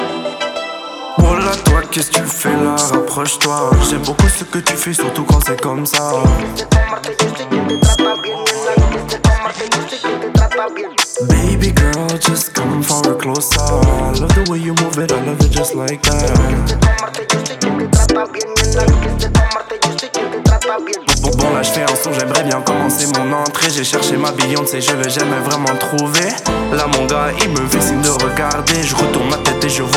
Жанр: Поп музыка / Латино
Urbano latino, Latin, French Pop